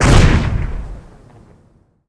Warcorrespondents/地雷爆炸.wav at 153454f3122d458e47f3efe309f37b91e8a3fb3f
地雷爆炸.wav